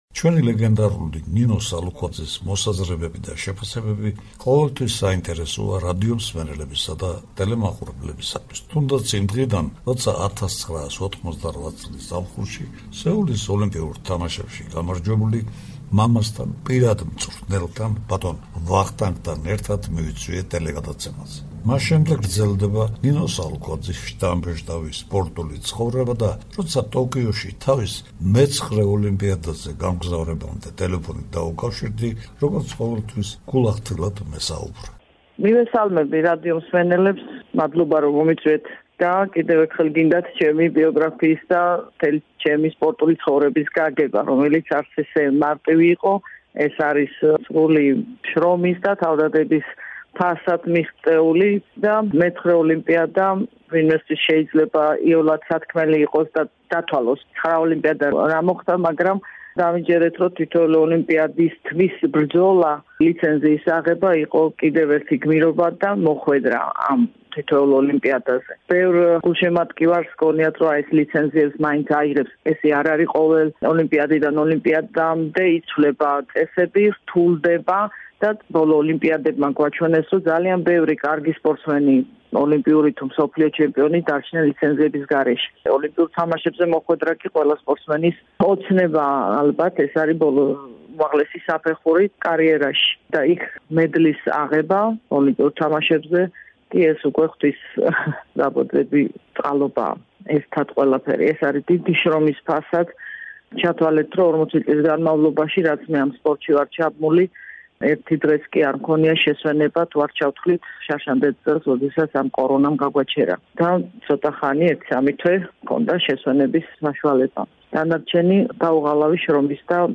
ლეგენდარული ნინო სალუქვაძე, ოლიმპიური ჩემპიონი და სხვა ყველა უმაღლესი სპორტული ტიტულის მფლობელი, რომელიც მეცხრედ გამოვა ოლიმპიურ ასპარეზზე, ტოკიოში გამგზავრებამდე მიცემულ ინტერვიუში რადიომსმენელებს გაუზიარებს თავისი სპორტული ცხოვრების განცდებსა და შთაბეჭდილებებს;